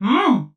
Sound of Prince Dreambert's voice from Mario & Luigi: Dream Team
MLDT_-_Dreambert's_Voice.oga.mp3